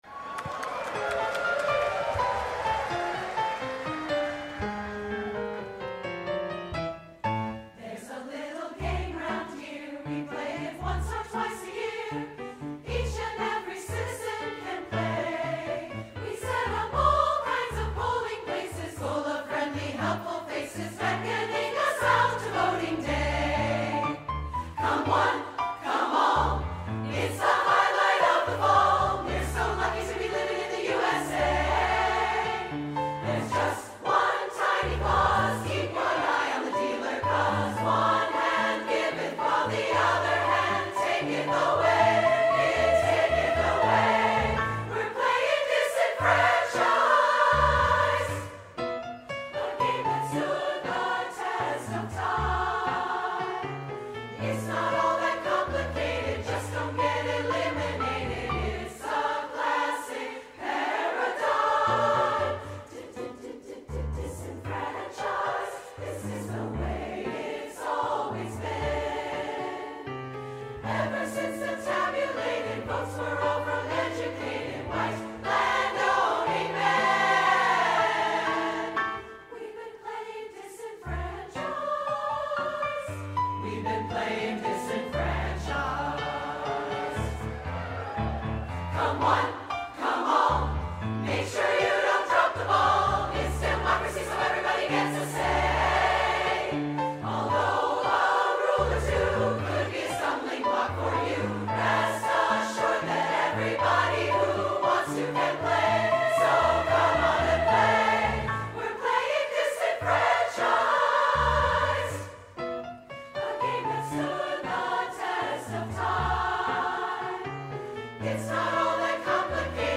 SSA, piano